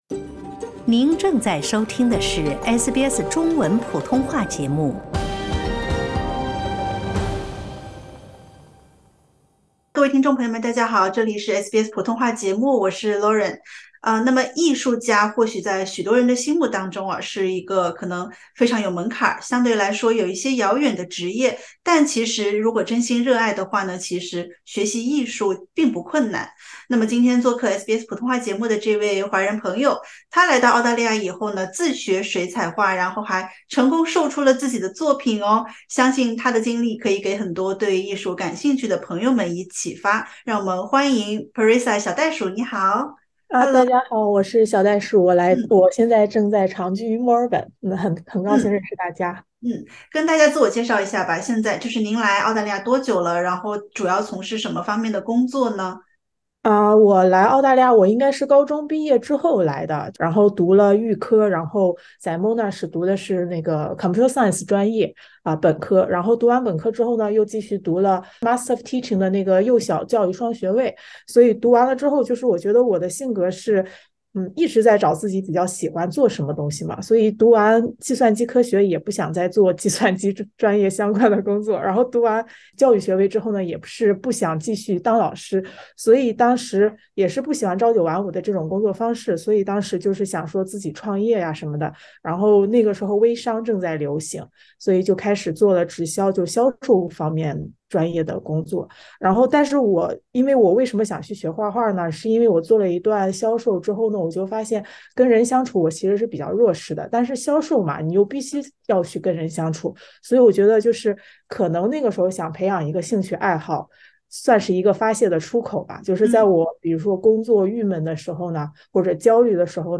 （点击收听完整采访） 欢迎下载应用程序SBS Audio，关注Mandarin。